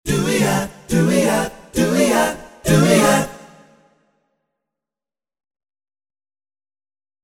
Dooeeot demo =3-B02.mp3